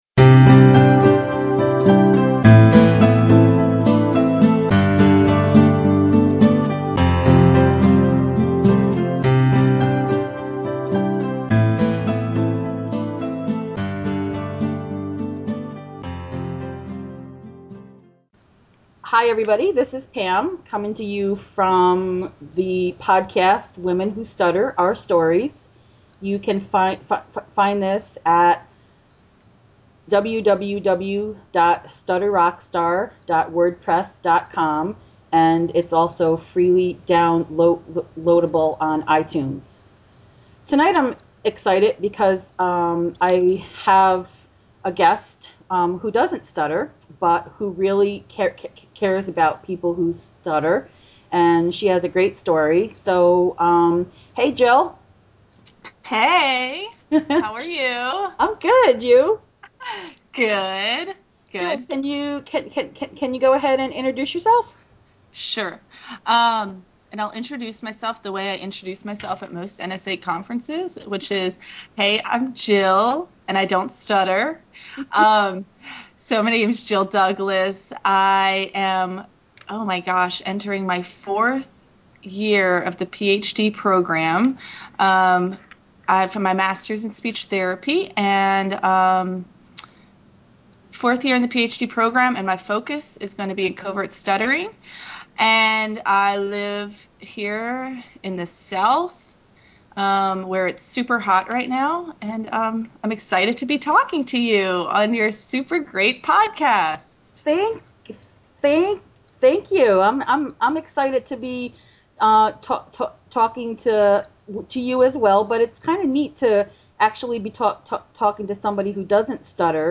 Episode 13 (Lucky # 13) is a great conversation about stuttering with a woman who doesn’t stutter.